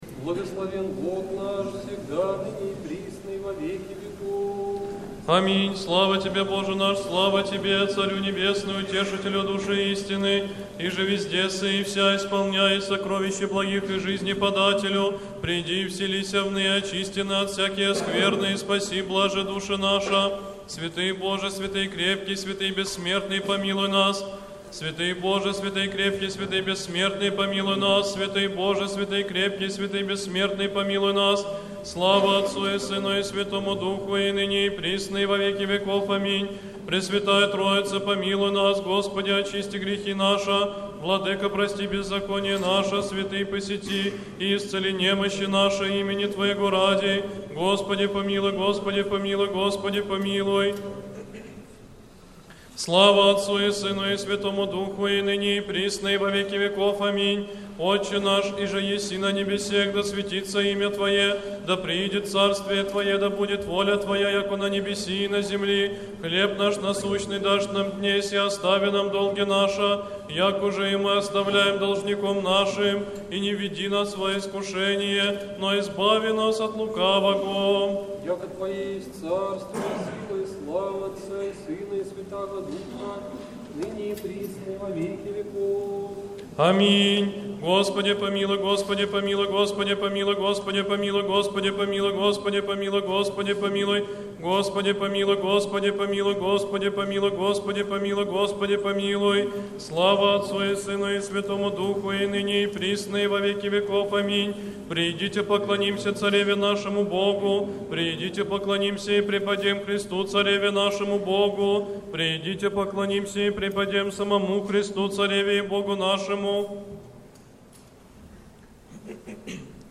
Вечерня, чин прощения. Хор Сретенского монастыря.
Вечерня с чином прощения в Сретенском монастыре